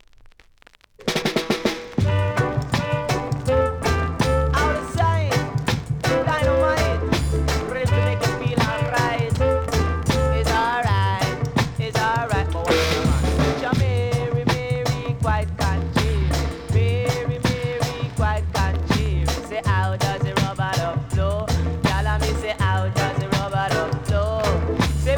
REGGAE 70'S